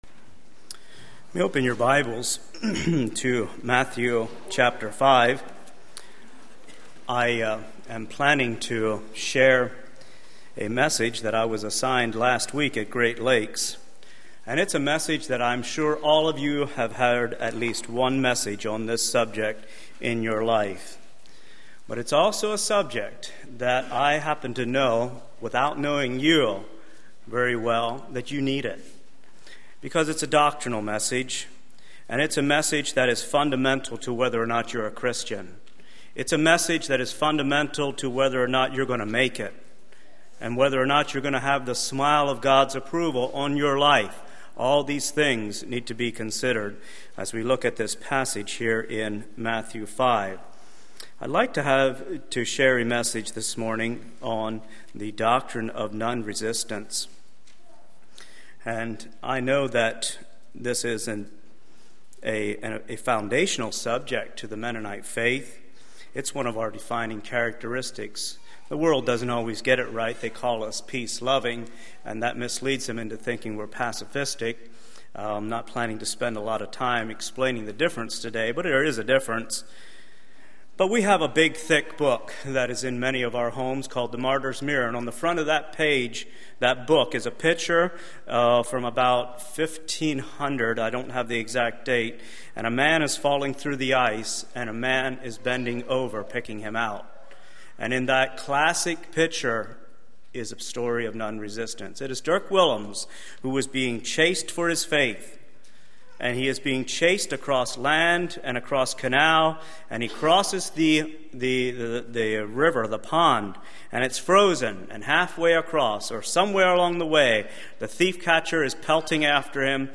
2015 Sermon ID